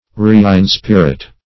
Reinspirit \Re`in*spir"it\